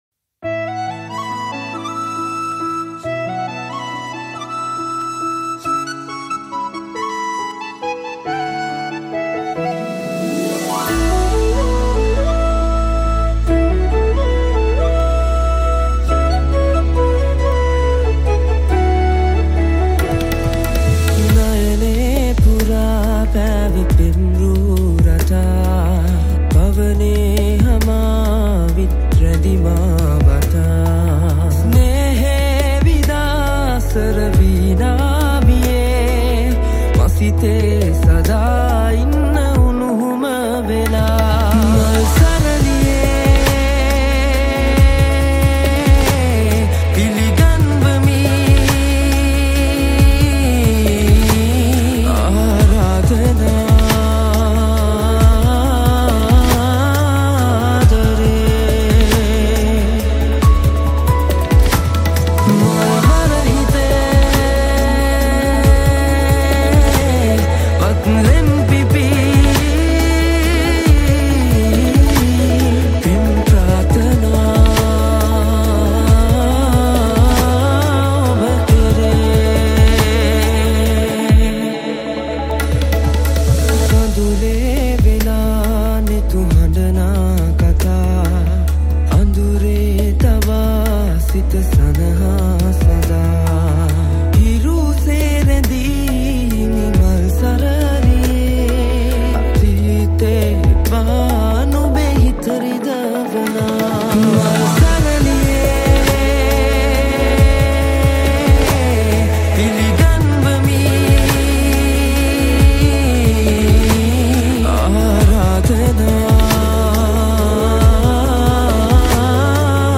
Recording Studio